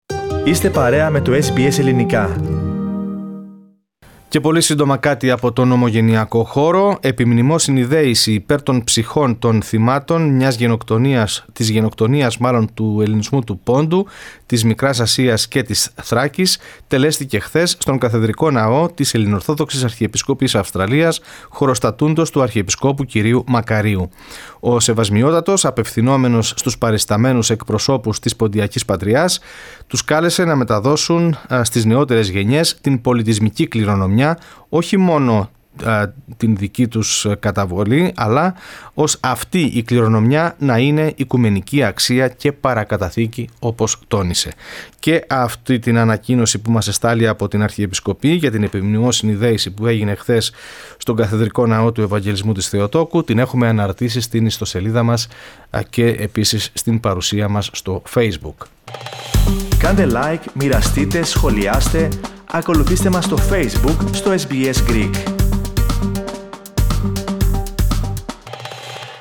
Επιμνημόσυνη δέηση υπέρ των ψυχών των θυμάτων της Γενοκτονίας του Ελληνισμού του Πόντου, της Μικράς Ασίας και της Θράκης (1914-1924) τελέσθηκε χθες στον Καθεδρικό Ναό της Ελληνορθόδοξης Αρχιεπισκοπής Αυστραλίας, χοροστατούντος του Αρχιεπισκόπου κ. Μακαρίου.
Memorial service at theGreek-Orthodox Cathedral in Sydney for the Pontian genocide Source: Supplied